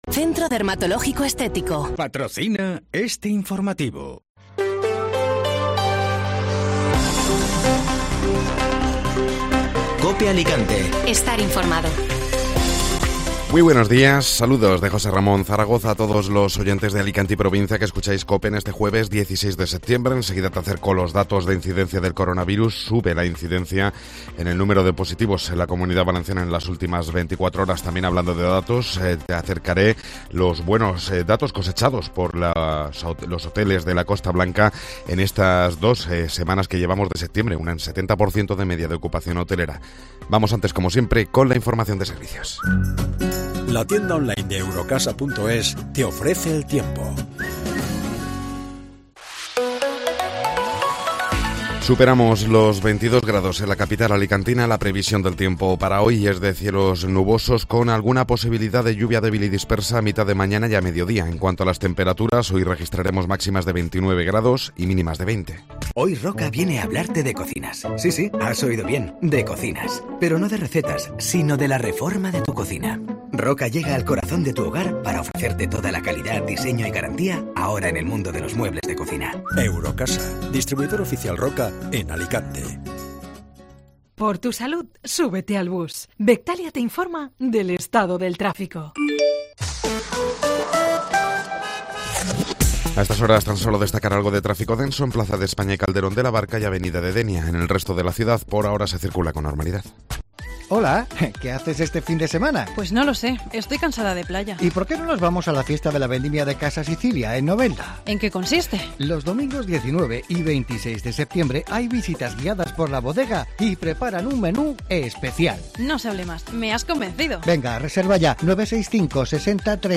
Informativo Matinal (Jueves 16 de Septiembre)